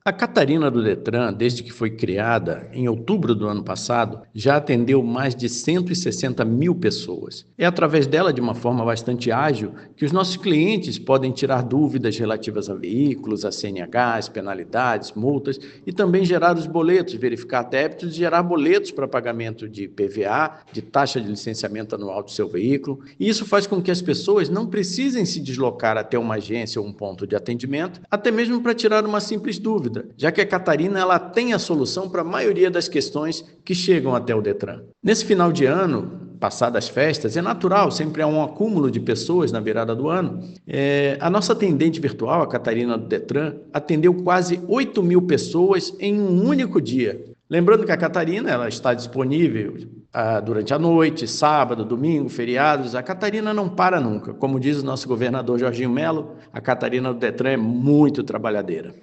O vice-presidente do Detran/SC, Ricardo Miranda Aversa, explica os serviços oferecidos por meio da atendente virtual:
SECOM-Sonora-Vice-Presidente-DetranSC-Inteligencia-Artificial.mp3